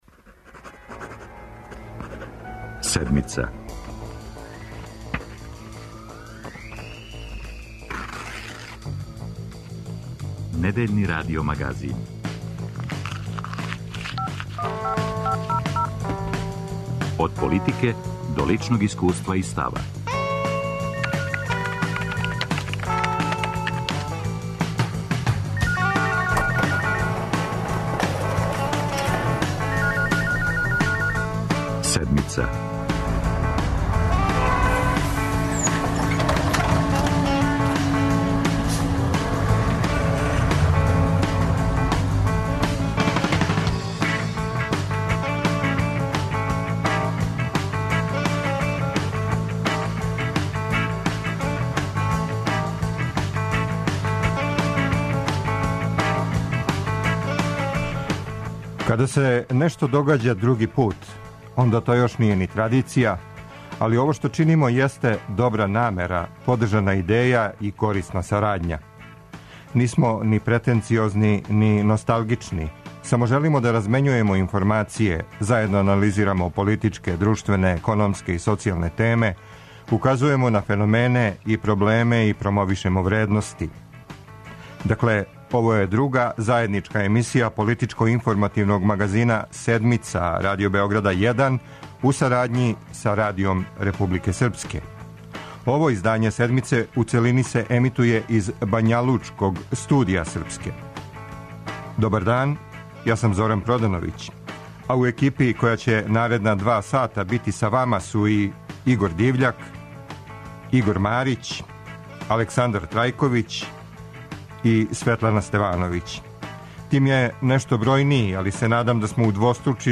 Заједничка емисија Радио Београда 1 и Радија Републике Српске.